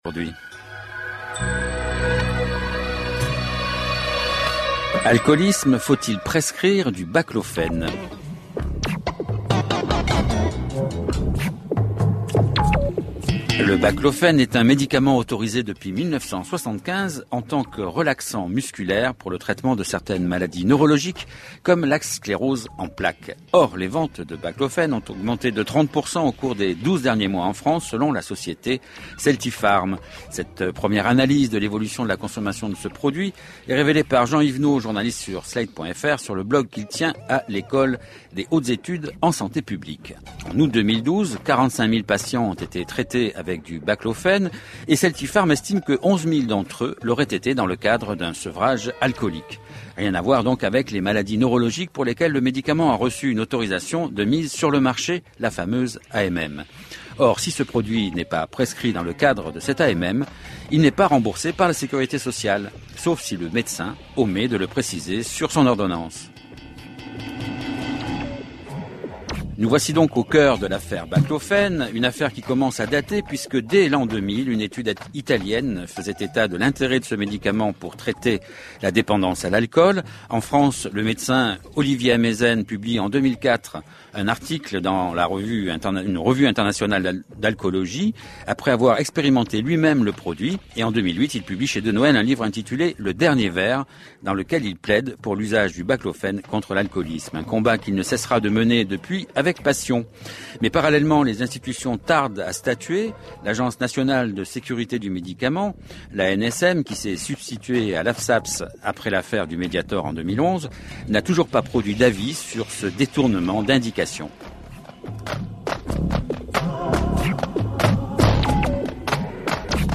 Une émission de France culture vendredi 7 septembre à 14h